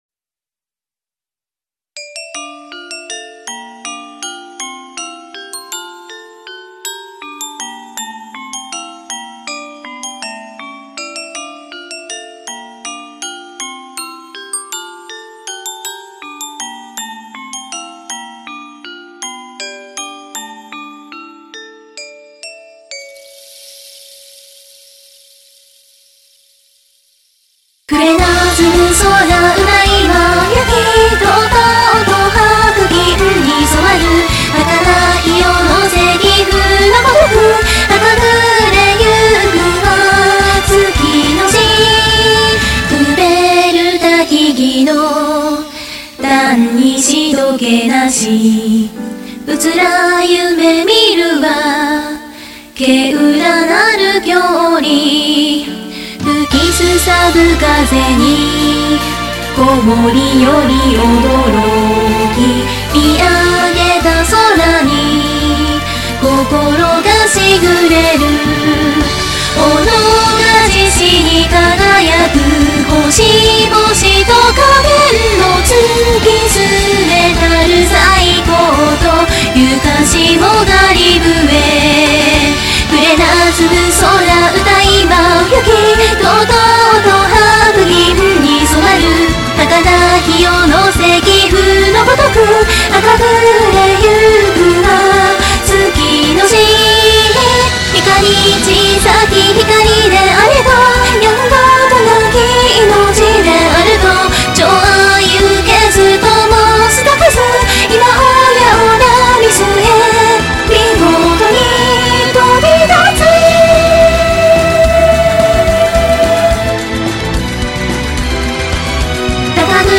ひとりでユニゾンしております←録音からMIXまで2時間クオリティなので若干走り気味;;
(音量注意)